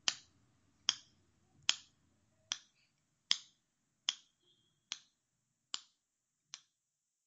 时钟
描述：井字壁钟。立体声
Tag: 挂钟 滴答滴答 井字